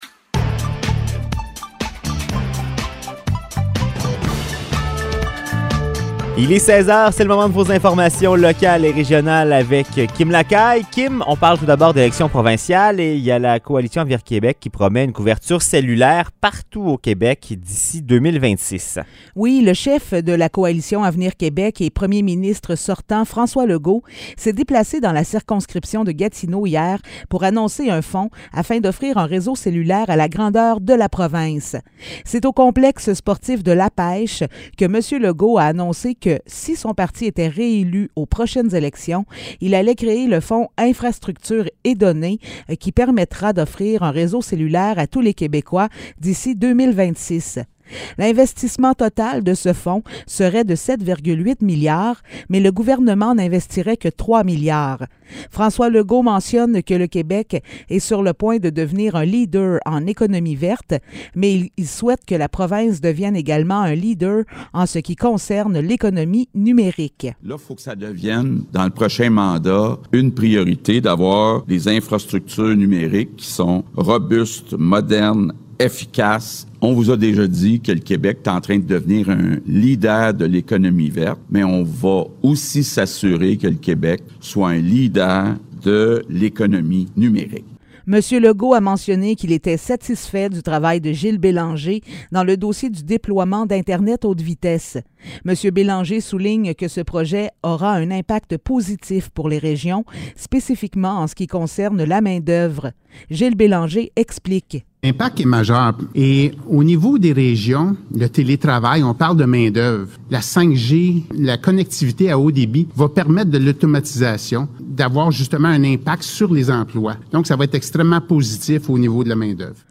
Nouvelles locales - 6 septembre 2022 - 16 h